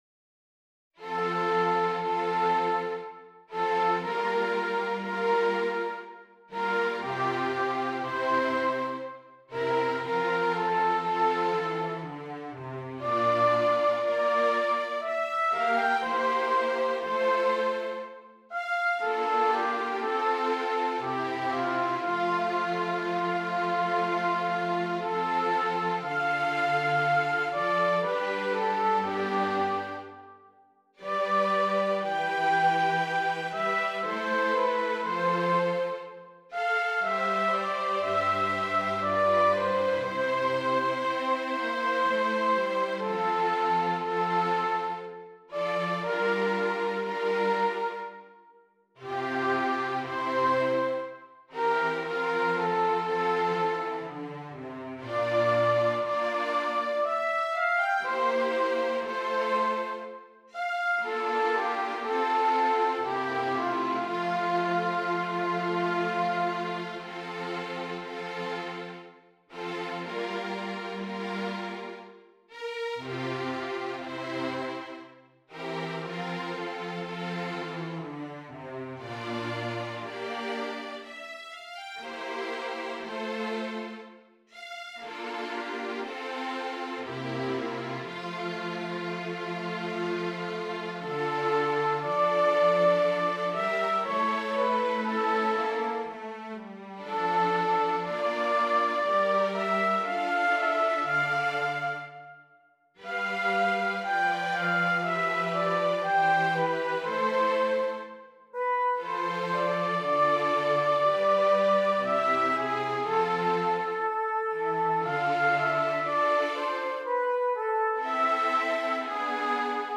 Trumpet and Strings